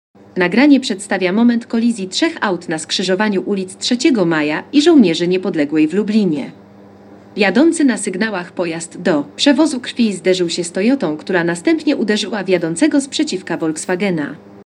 Nagranie audio Audiodeskrypcja_kolizja_trzech_aut_.mp3